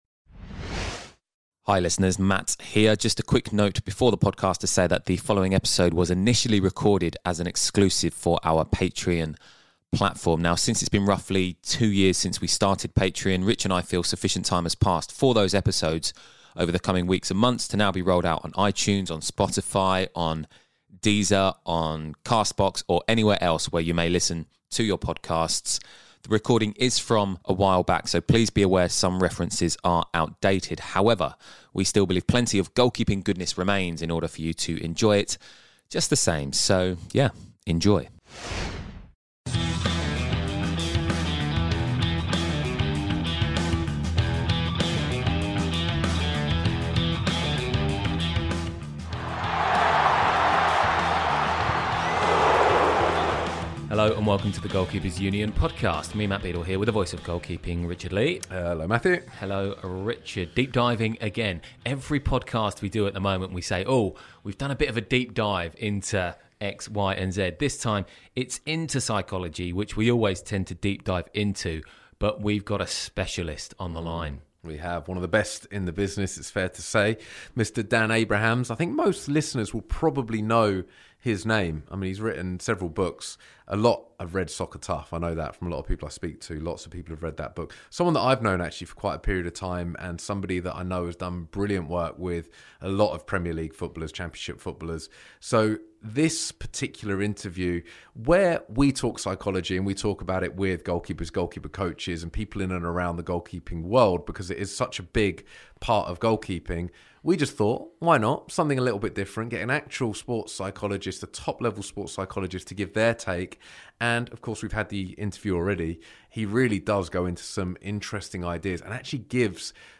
NB: This was initially recorded for our Patreon platform.